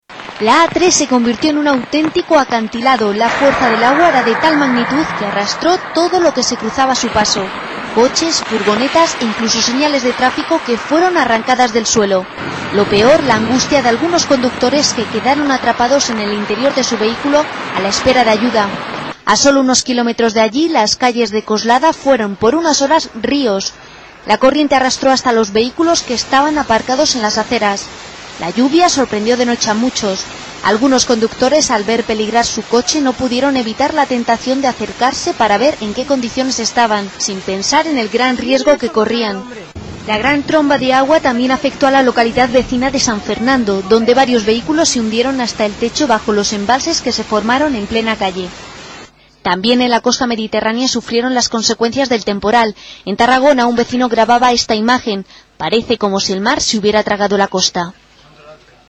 Criterios de evaluación: soy audible, pronuncio bien, consigo leer a la misma velocidad que la locutora.